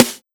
SNARE110.wav